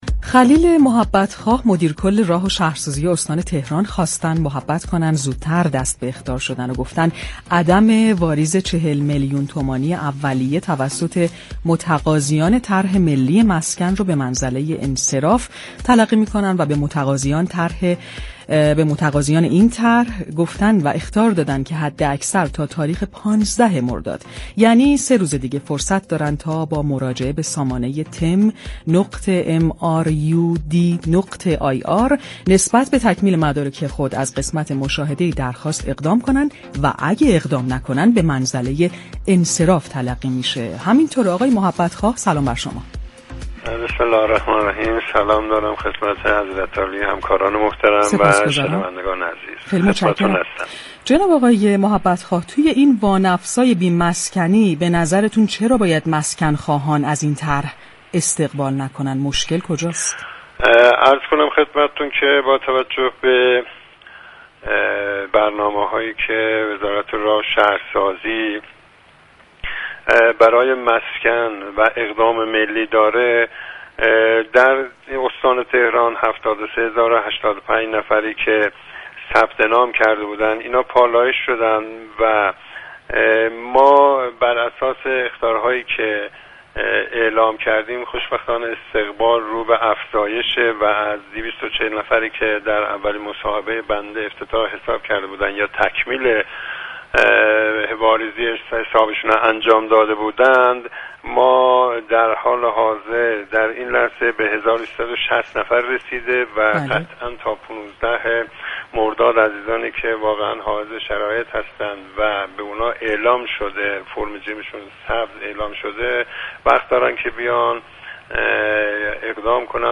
خلیل محبت خواه، مدیركل راه و شهرسازی استان تهران در خصوص مهلت و شرایط افراد واجد شرایط درخواست مسكن ملی با بازار تهران گفت و گو كرد.